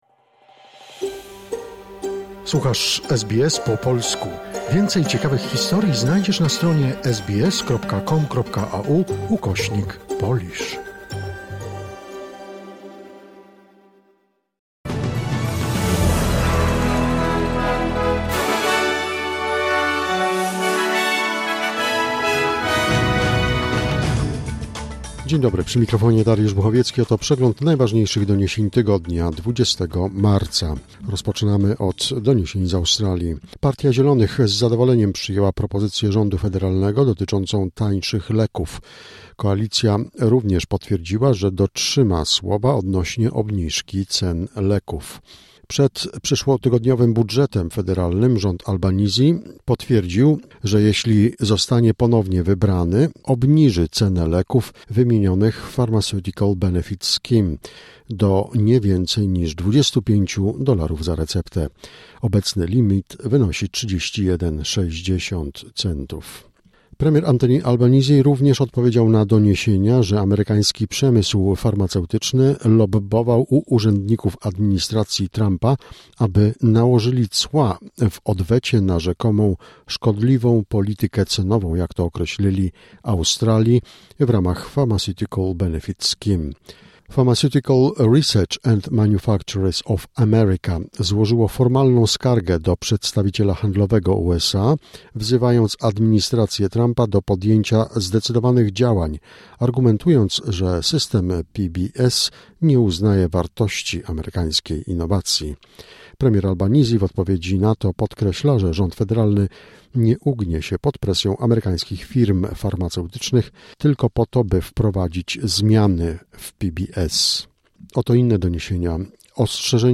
Wiadomości 20 marca SBS Weekly Wrap